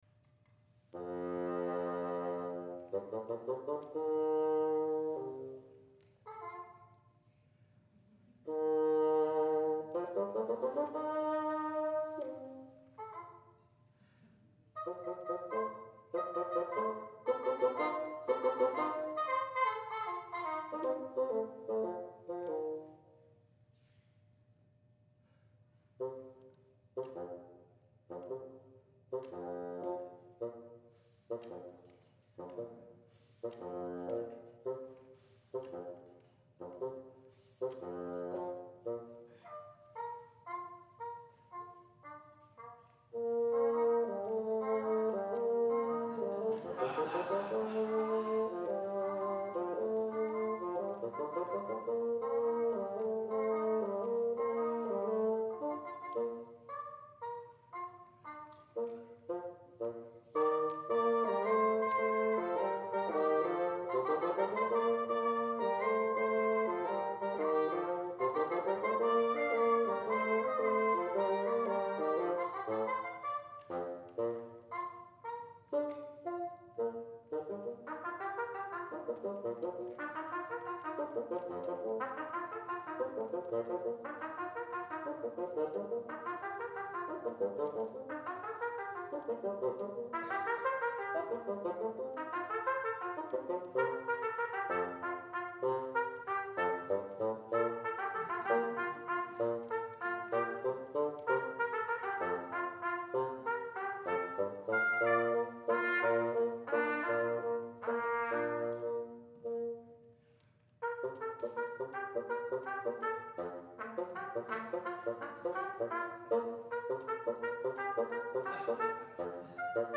for Trumpet and Bassoon (2006)
trumpet
bassoon.